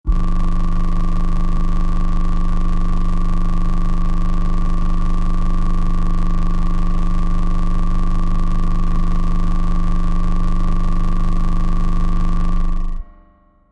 描述：一些嗡嗡声
Tag: 数字 FX 苛刻